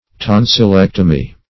Tonsillectomy \Ton`sil*lec"to*my\, n. (Surg.)